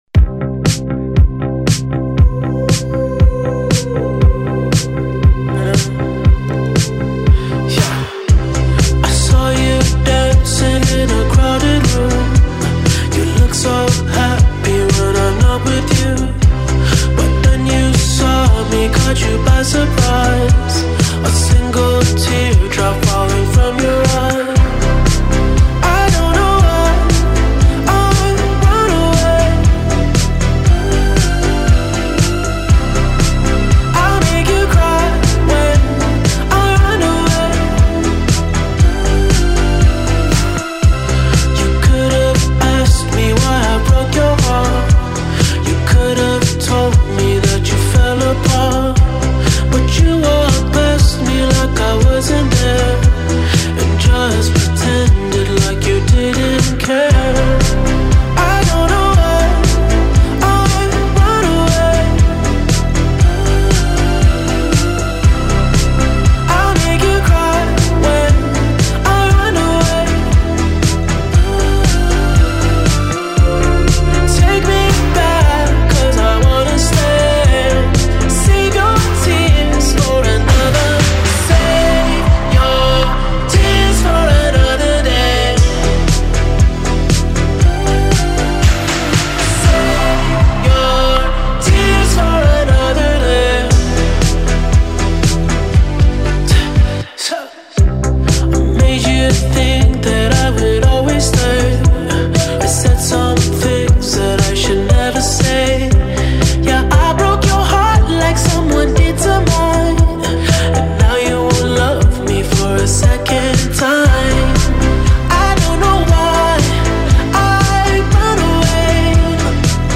2020 سبک: R&B _ POP